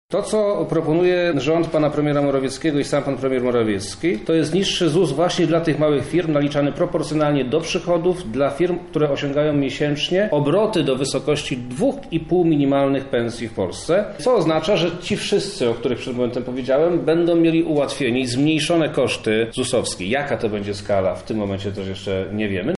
,,Mała firma – mały ZUS” to kolejny punkt z planu Mateusza Morawieckiego. O jego szczegółach mówi Wojewoda Lubelski, Przemysław Czarnek: